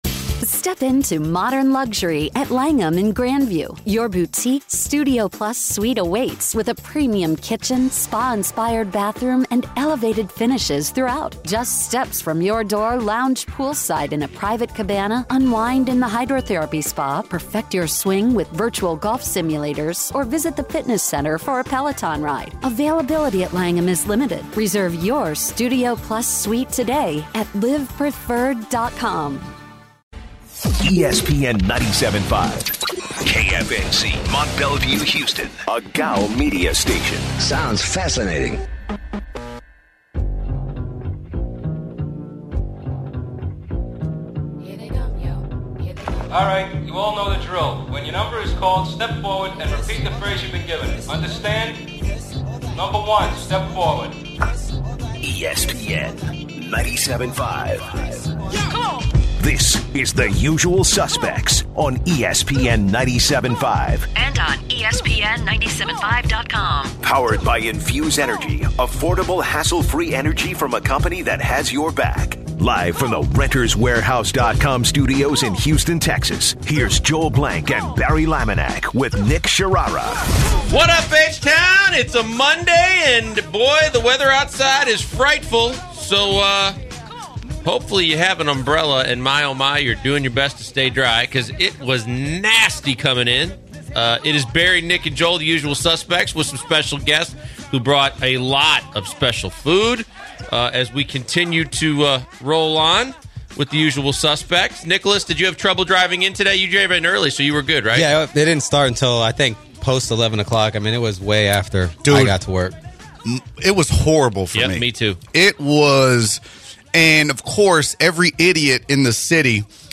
Fay Vincent, former MLB Commissioner, calls in for an interview and talks about how gambling in sports could soon be legalized. They finish the hour by talking about automated strike zones, and whether they’re about to take over the game.